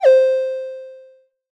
SFX_close.ogg